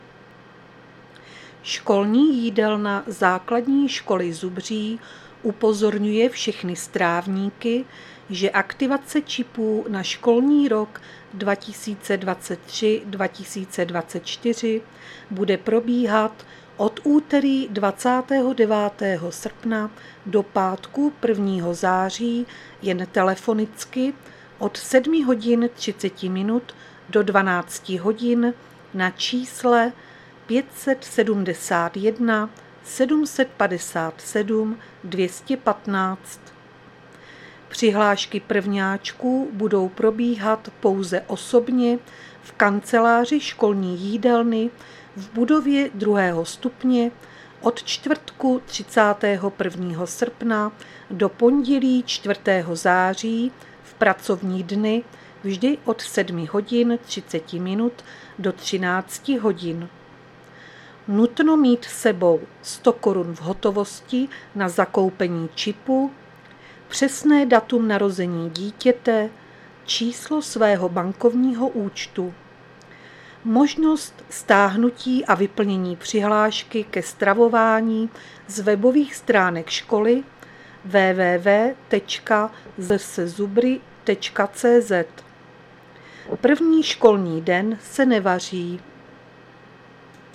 Záznam hlášení místního rozhlasu 28.8.2023